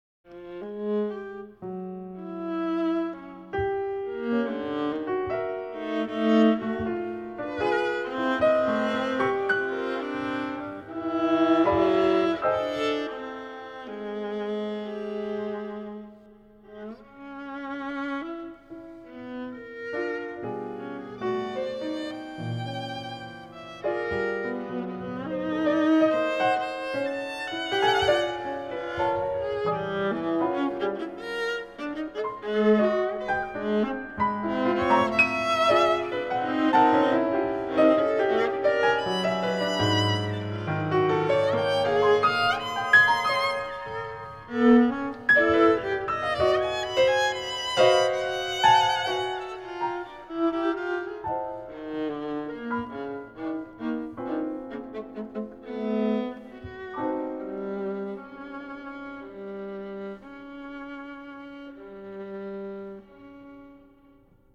LISTEN 7 Sonata for Viola and Piano (1979)